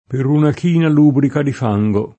lubrico [l2briko; non -br&-] agg.; pl. m. ‑ci (non -chi) — es.: Per una china lubrica di fango [